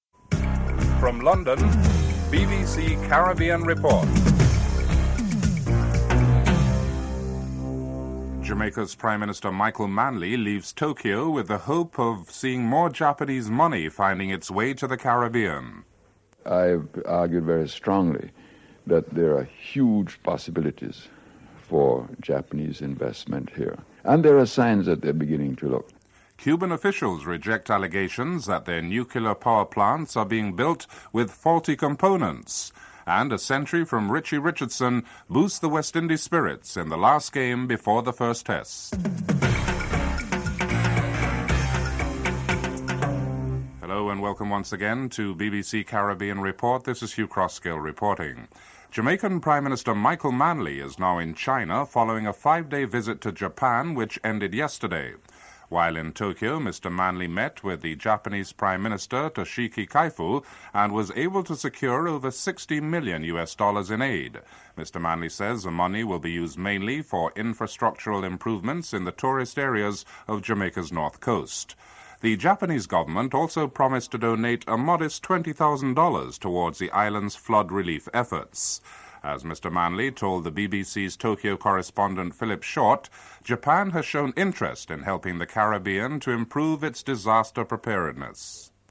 1. Headlines (00:00-00:43)